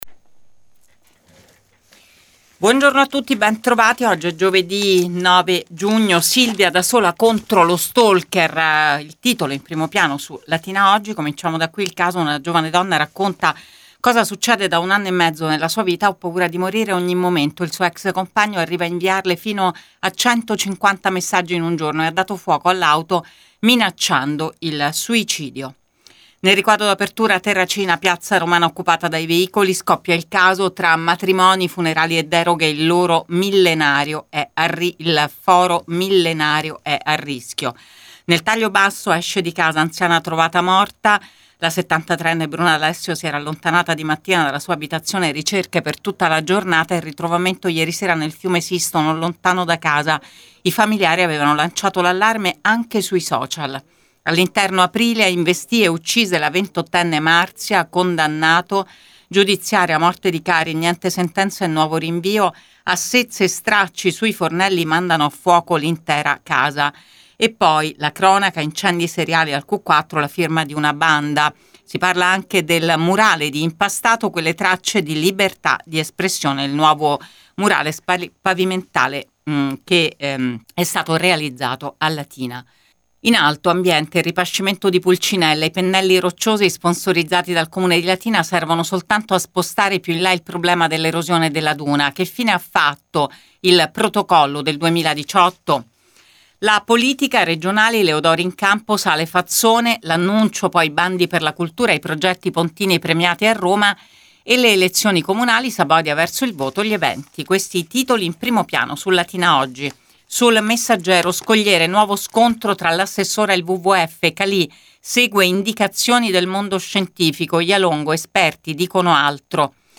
LATINA – Qui trovate Prima Pagina, ora solo in versione web, per dare uno sguardo ai titoli di Latina Editoriale Oggi e Il Messaggero Latina. (audio dopo la pubblicità)